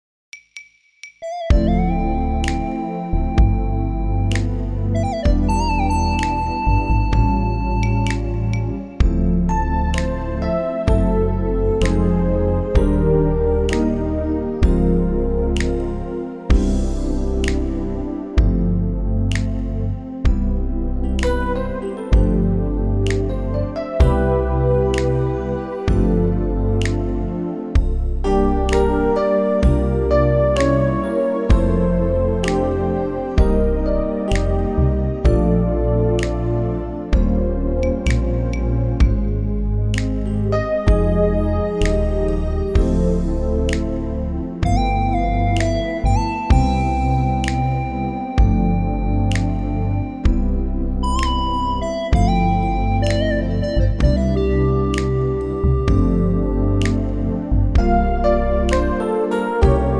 Unique Backing Tracks
key - C - vocal range - C to D
Super smooth arrangement